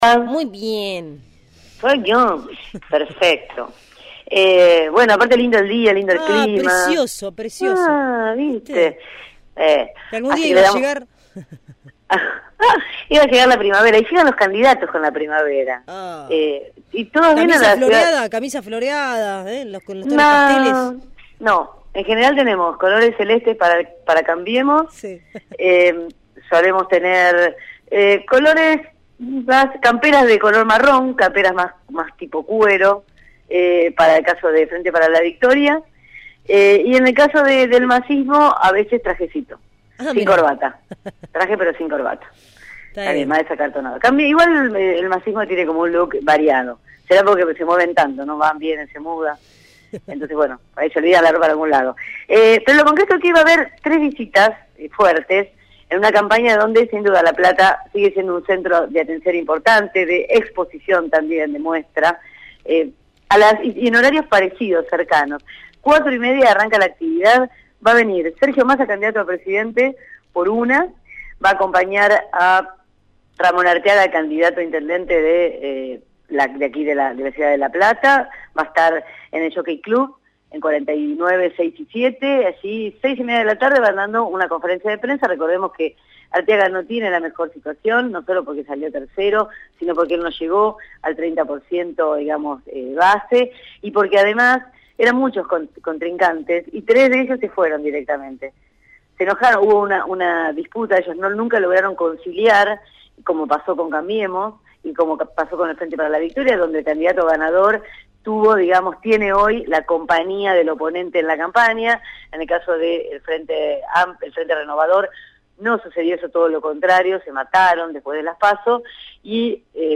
realizó su habitual informe sobre la actualidad política bonaerense. En esta oportunidad se refirió a tres visitas importantes que recibirá  la ciudad en el día  de hoy en el marco del último tramo de la campaña tanto local como provincial y nacional.